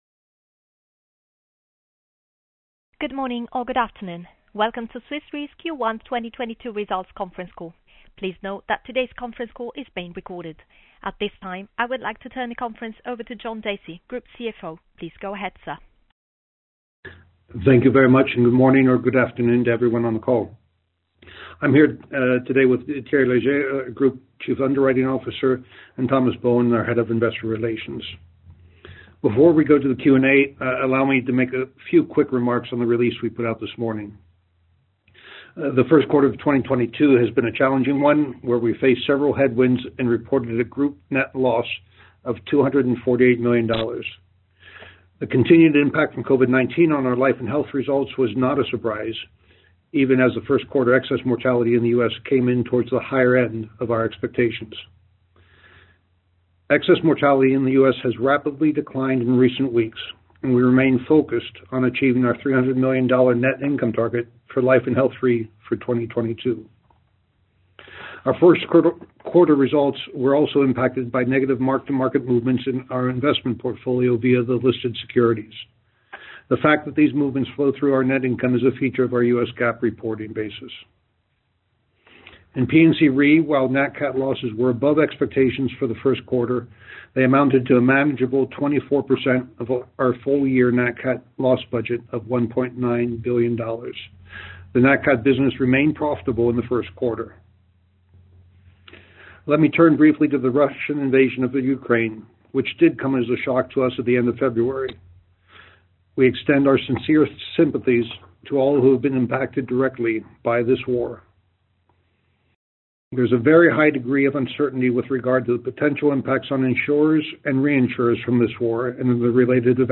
q1-2022-call-recording.mp3